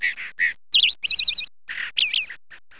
BIRD.mp3